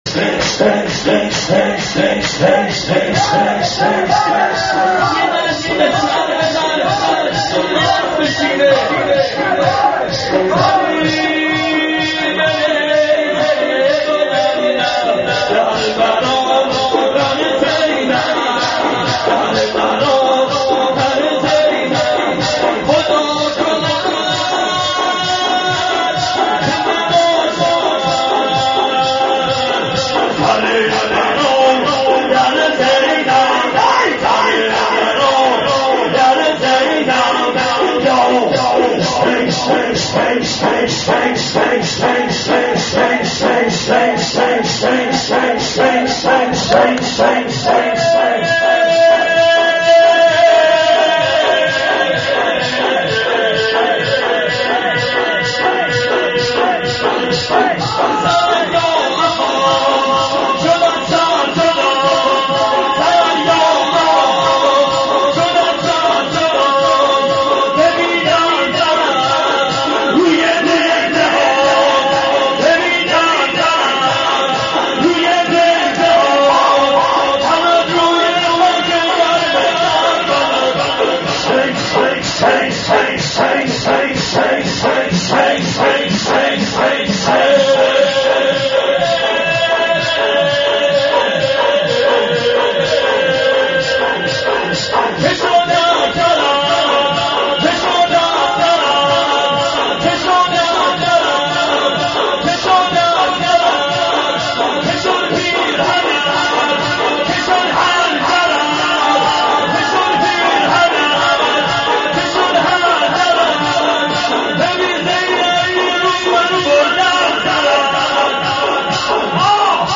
شعر خوانی و ذکر و نغمه اباعبدالله.MP3